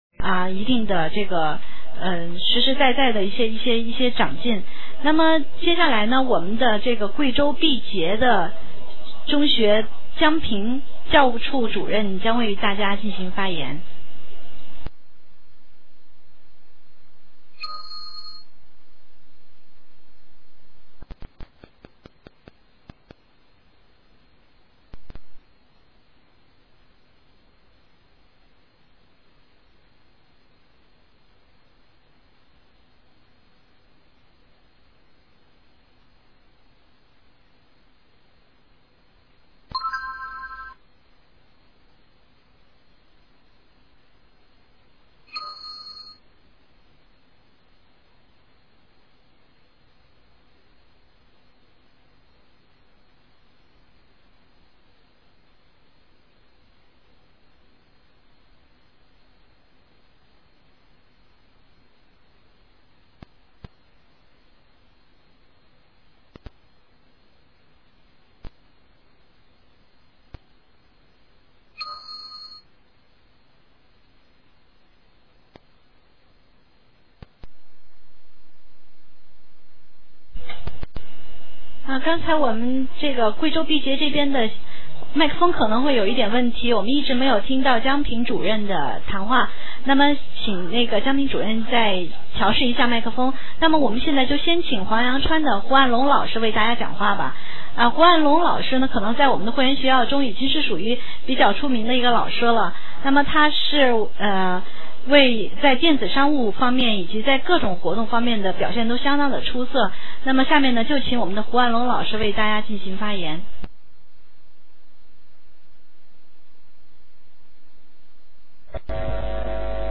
开学日现场录音１，２，３